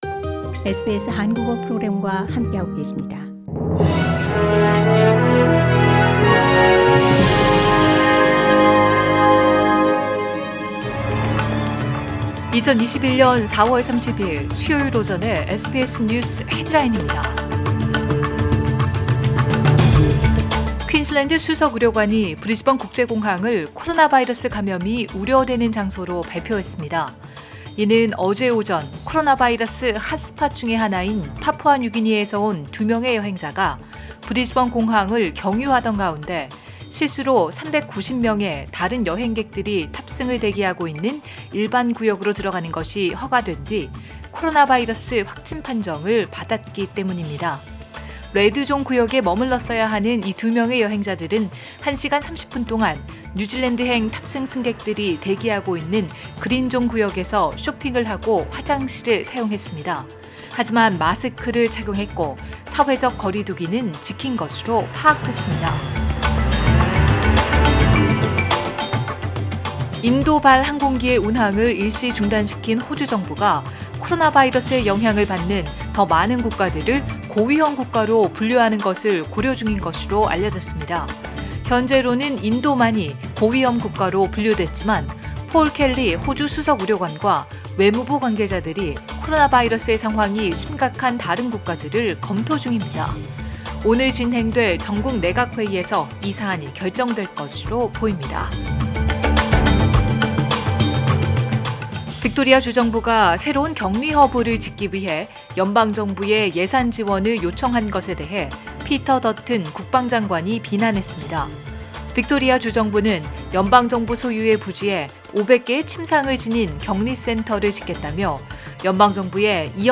2021년 4월 30일 수요일 오전의 SBS 뉴스 헤드라인입니다.